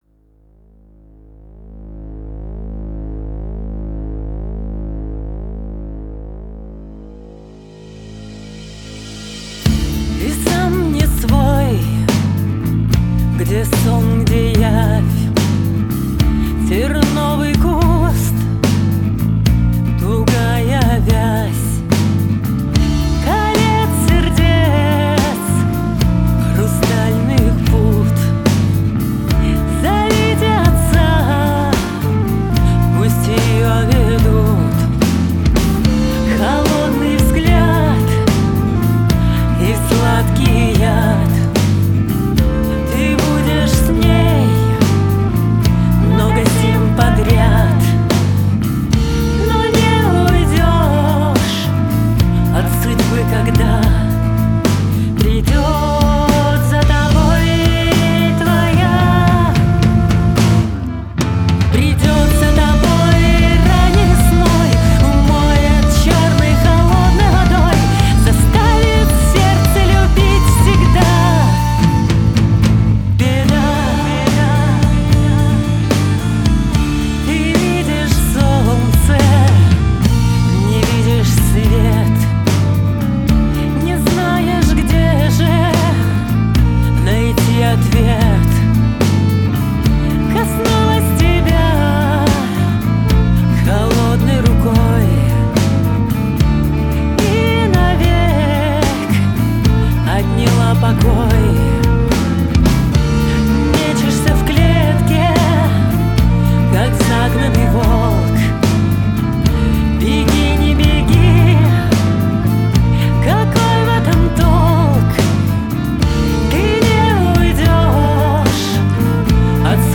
Русские песни